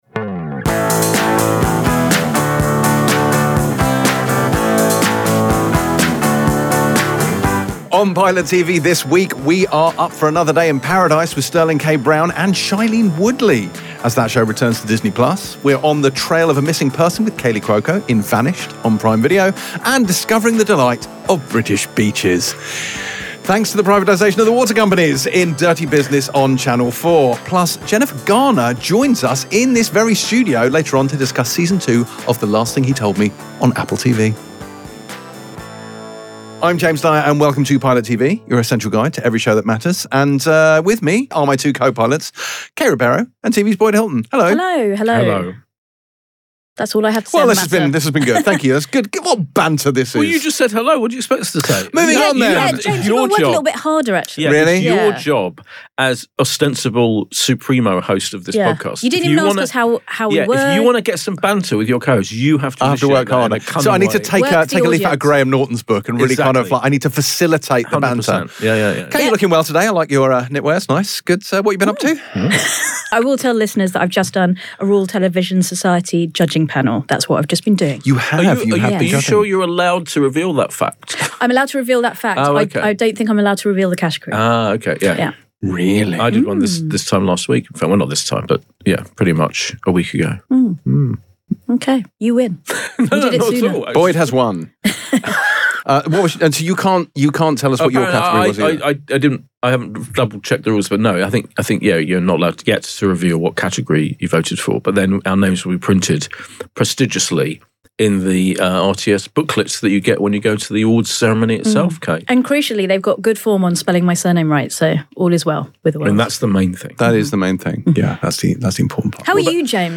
Paradise, Vanished, and Dirty Business. With guest Jennifer Garner